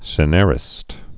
(sĭ-nârĭst, -när-, -năr-)